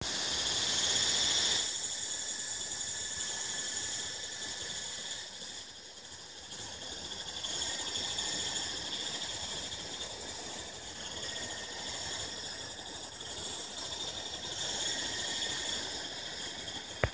forest_sound.mp3